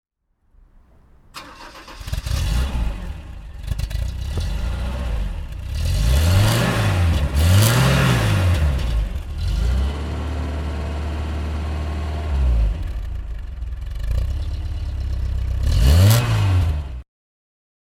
Toyota Cressida 2000 GL (1982) - Starten und Leerlauf